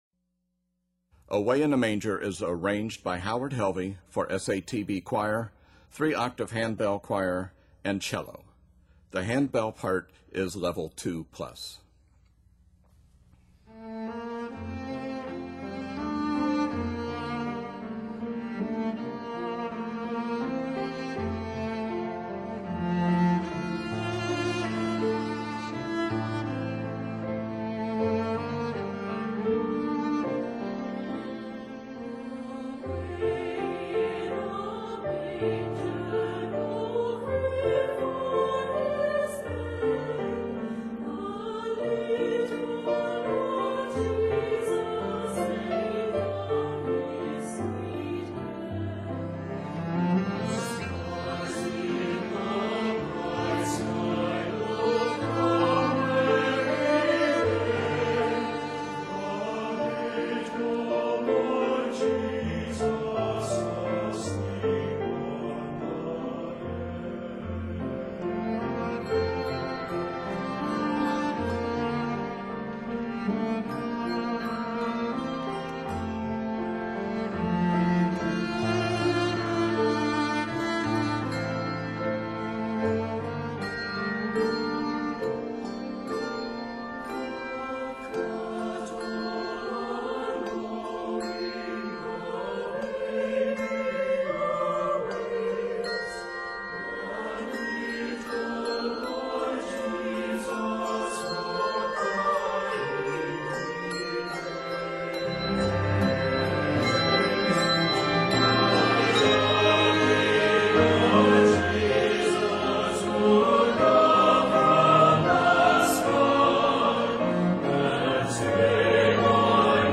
Varies by Piece Season: Christmas